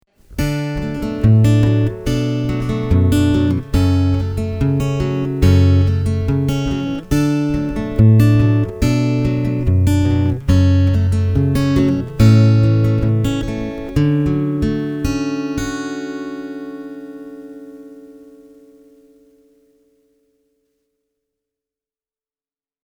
The G630CE sings with a beautiful acoustic tone – its tight and sinewy bottom end nicely leaves space for this guitar’s clear midrange and bell-like top end.
The B-Band-system goes a very long way in keeping the Walden’s acoustic timbre intact, and the amplified tones are far more realistic than you might expect from an under-saddle pickup.
Walden G630CE – B-Band – fingerstyle
The B-Band T35 looks like the perfect match for this model, thanks to its natural tone and low background noise level.